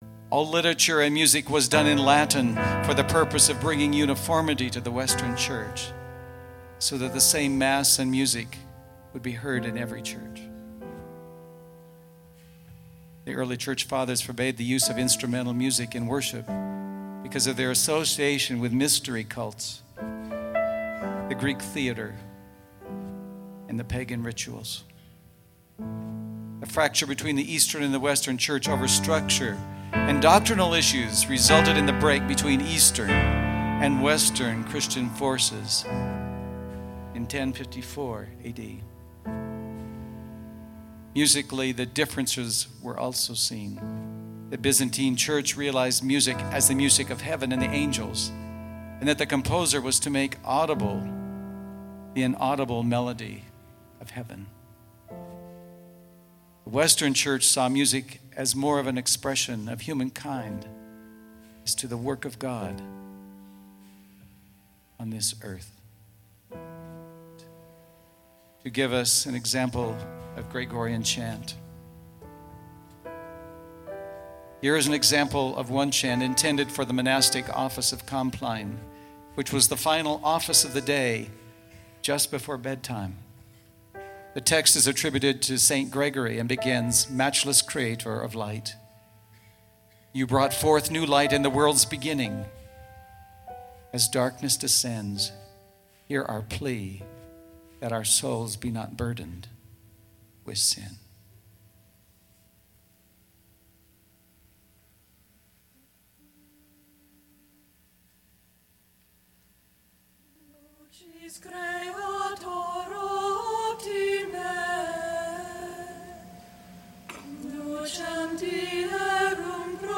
Recorded at the Renovaré International Conference in Denver, CO.